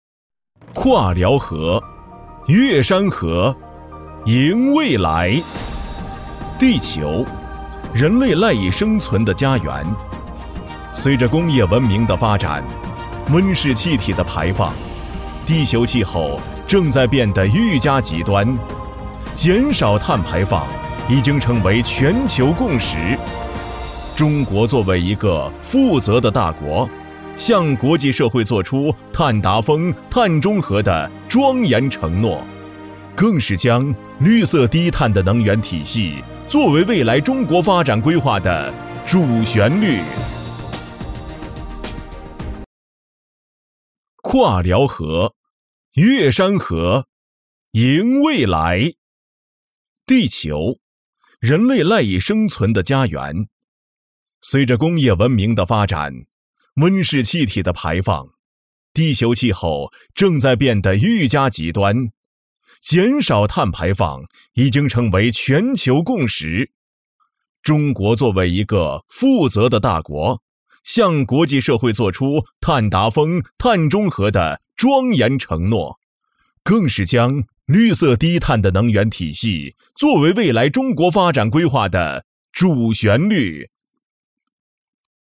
男24号配音师
专题片-男24-跨辽河 越山河 赢未来.mp3